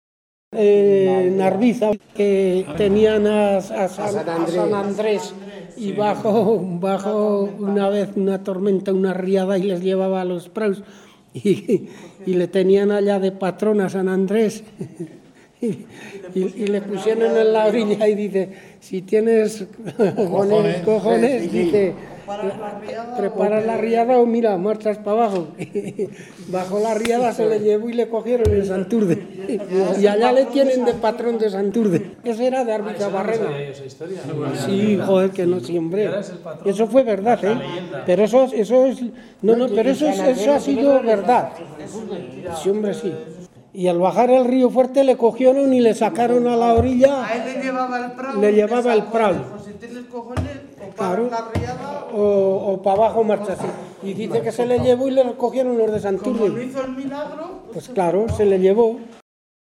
Clasificación: Dictados tópicos, cuentos
Lugar y fecha de recogida: Santurde de Rioja, 19 de noviembre de 2023